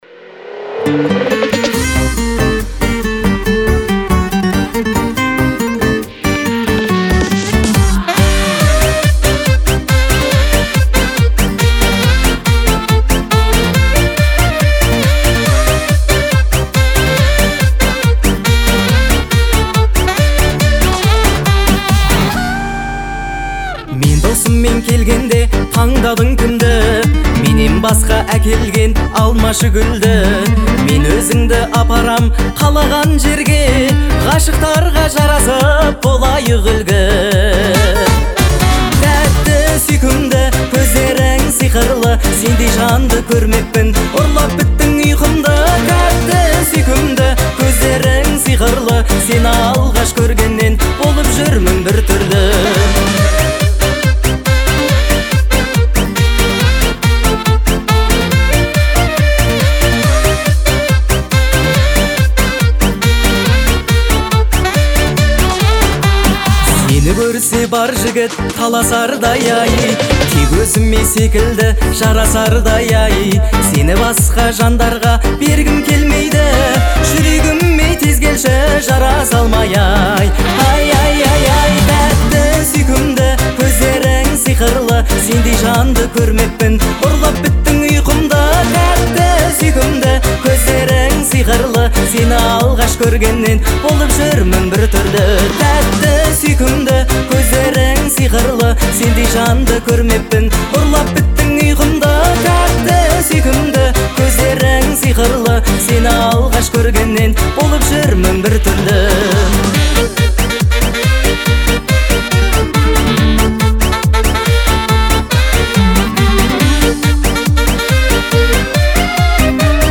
это яркий пример казахской поп-музыки